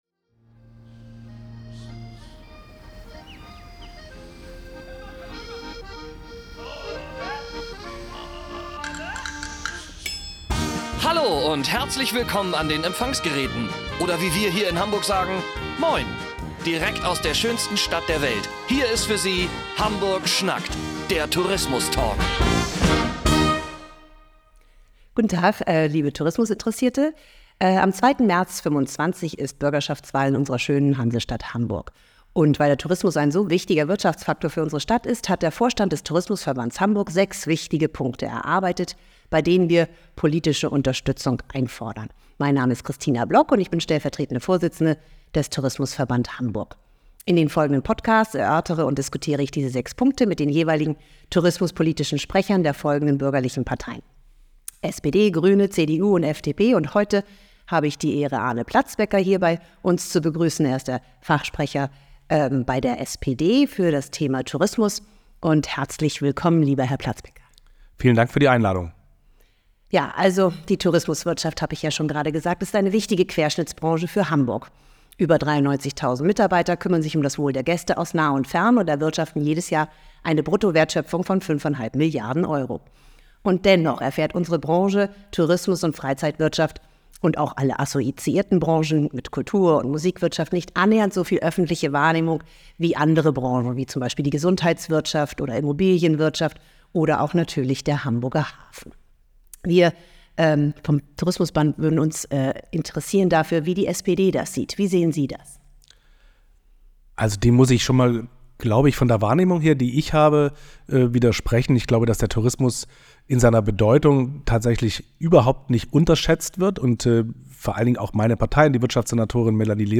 Hamburg schnackt – Der Tourismus Talk